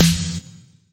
ELEC_SNR.wav